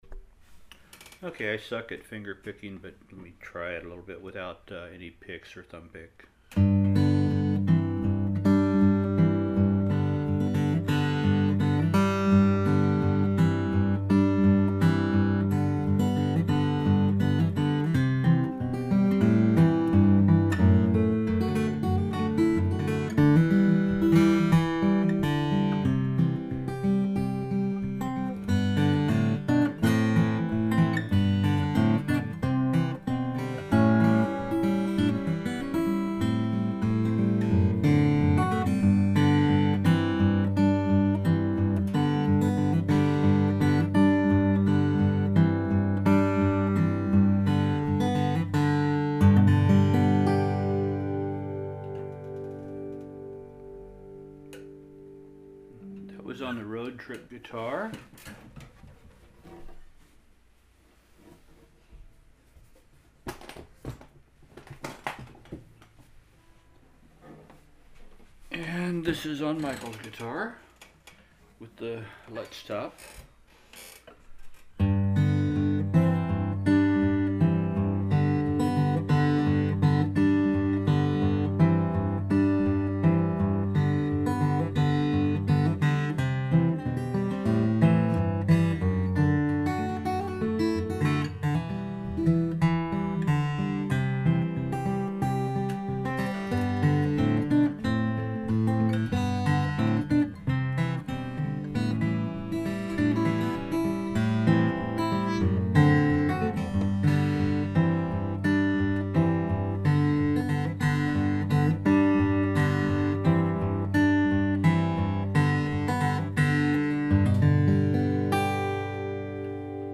Выбор пальца лапши и flatpick